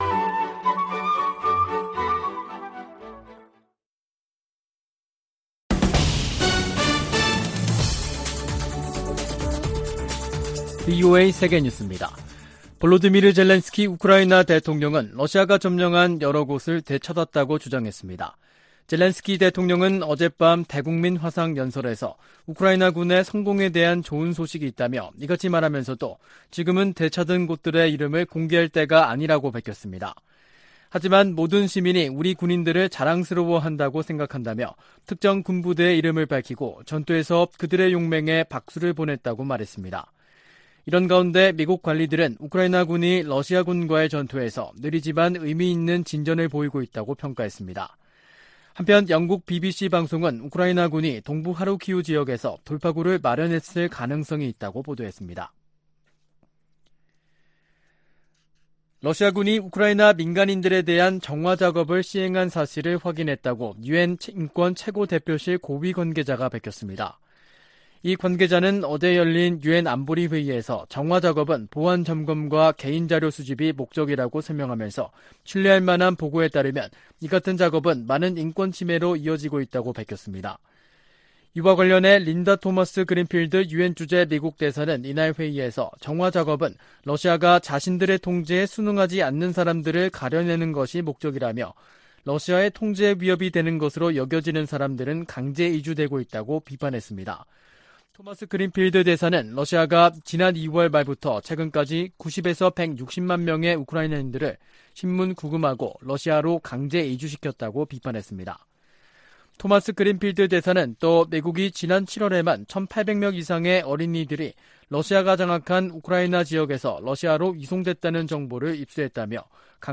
VOA 한국어 간판 뉴스 프로그램 '뉴스 투데이', 2022년 9월 8일 3부 방송입니다. 카멀라 해리스 미국 부통령이 오는 25일부터 29일까지 일본과 한국을 방문할 계획이라고 백악관이 밝혔습니다. 한국 외교부와 국방부는 제3차 미한 외교·국방 2+2 확장억제전략협의체(EDSCG) 회의가 오는 16일 워싱턴에서 열린다고 밝혔습니다. 한국 정부가 북한에 이산가족 문제 해결을 위한 당국간 회담을 공식 제안했습니다.